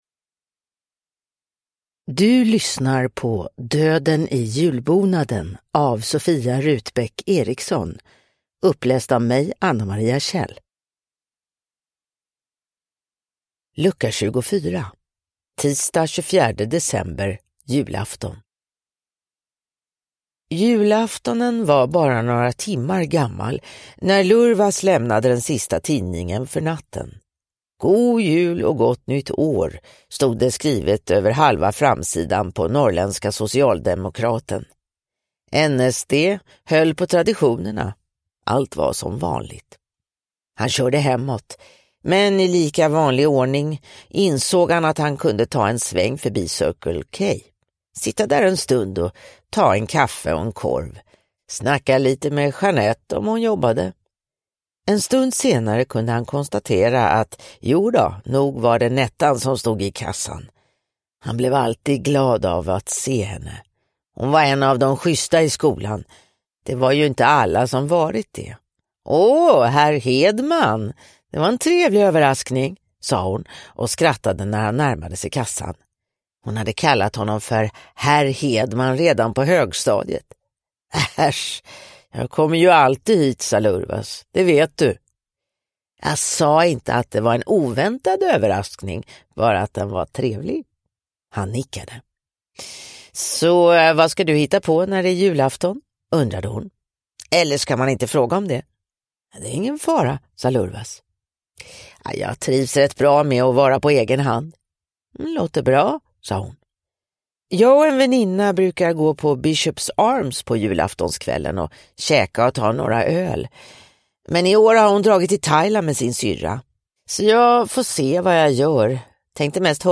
Döden i julbonaden: Lucka 24 – Ljudbok